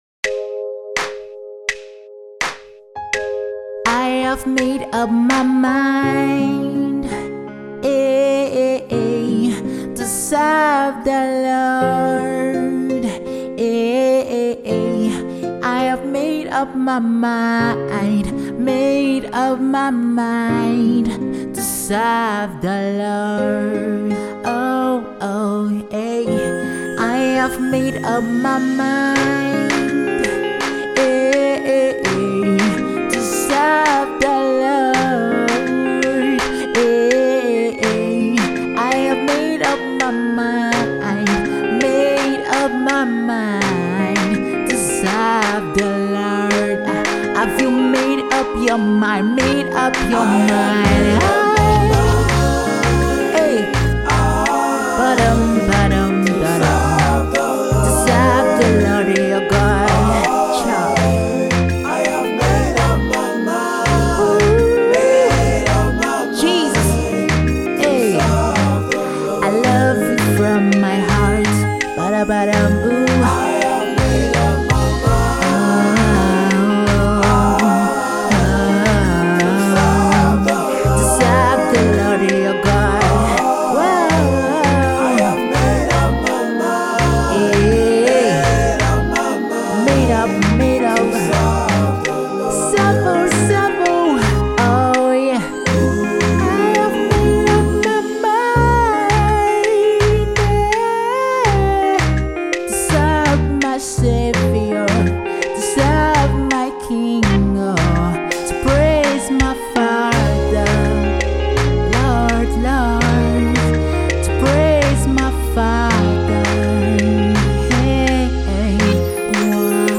an improvisation of the popular gospel song